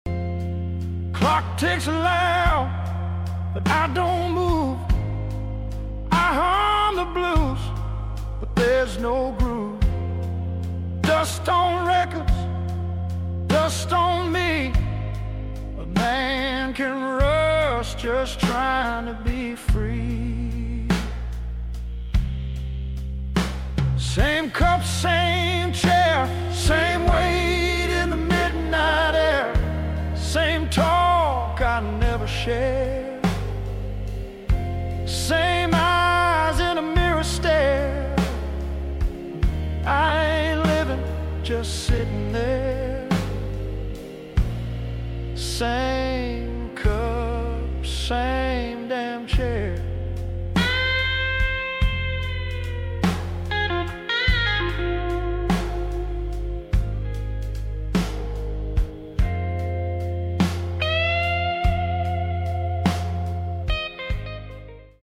Nostalgic blues forever